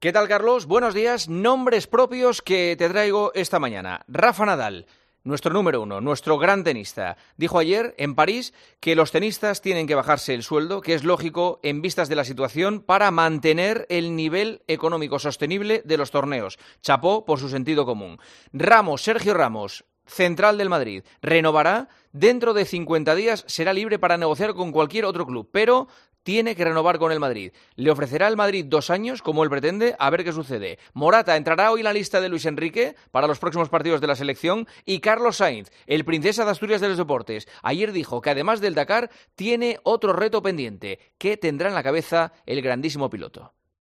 Juanma Castaño analiza la actualidad deportiva en 'Herrera en COPE'